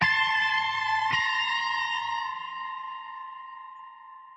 黑暗恐怖的声音 " 恐怖猜想的效果高110bpm - 声音 - 淘声网 - 免费音效素材资源|视频游戏配乐下载
在FL Studio中使用DSK VST引导吉他音色。应用效果：参数均衡，合唱和延迟。旋律在110bpm时音调较高